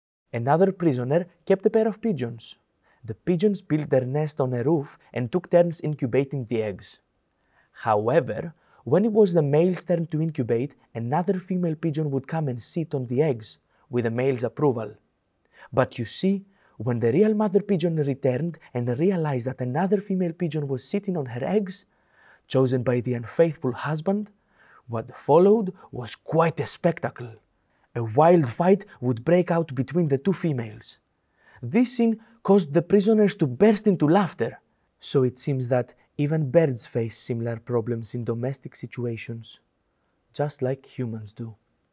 Audio dramatisation based on the Memoirs of a Prisoner.